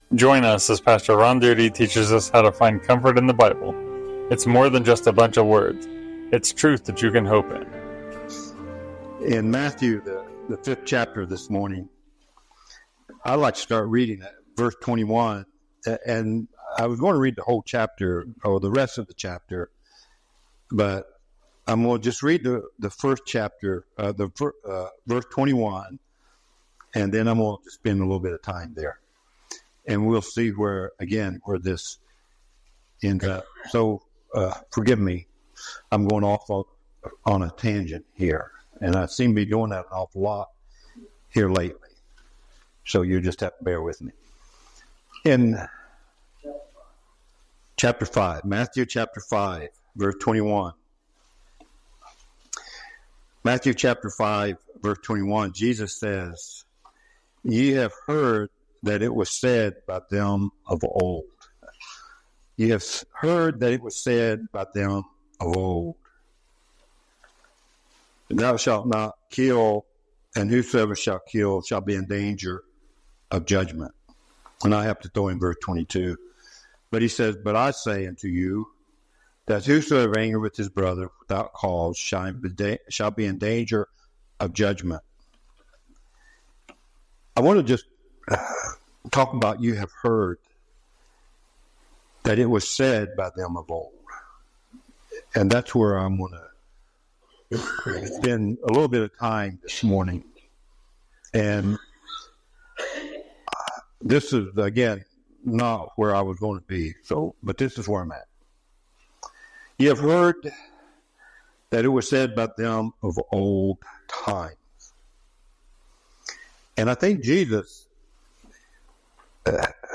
Sermons | First Baptist Church of Carroll